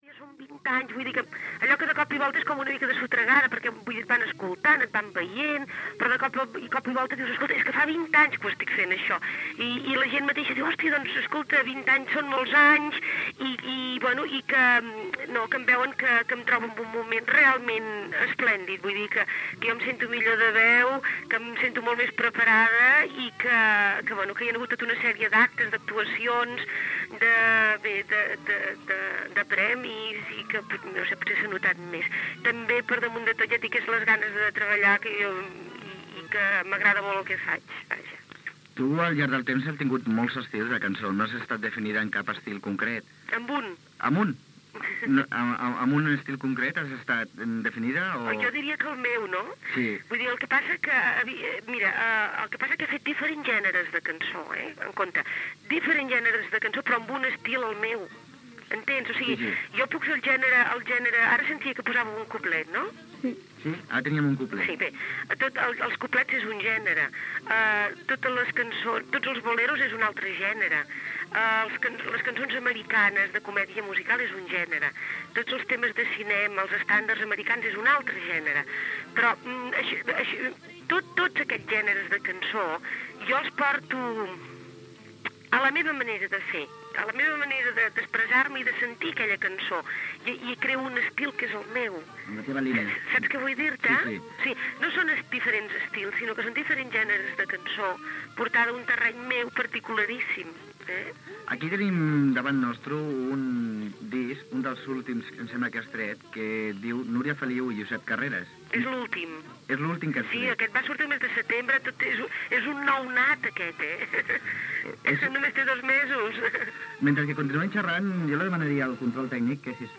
Entrevista telefònica a la cantant Núria Feliu: la seva actuació a València, el nou disc amb Josep Carreras.